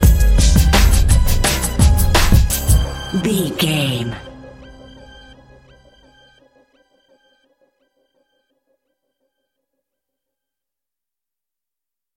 Aeolian/Minor
D
drum machine
synthesiser
hip hop
soul
Funk
energetic
bouncy
funky